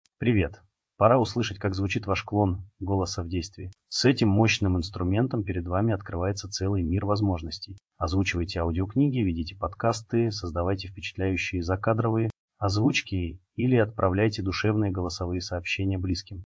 Мы собрали оригинальную запись и три клона, чтобы вы могли сами послушать и сравнить качество.
• Speechify — клон голоса
SPEECHIFY_VOICECLONE.mp3